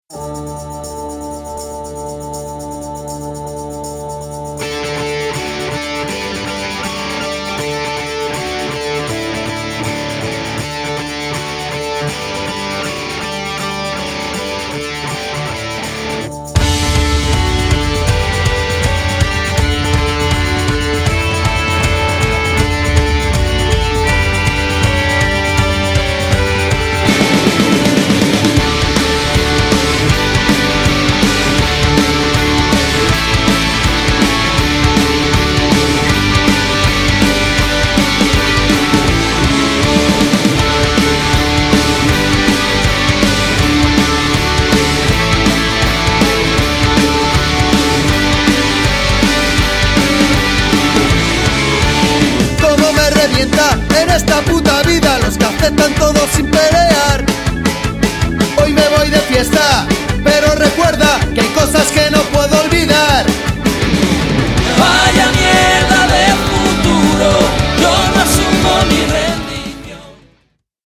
そのサウンドはストレートで全開、CDからも充分に伝わるパワーに熱くなることは必至！
そのエキゾチックなメロディが東欧ジプシー音楽風味を色漬け、新鮮さをプラスしている。